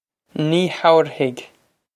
Pronunciation for how to say
Nee how-er-hig
This is an approximate phonetic pronunciation of the phrase.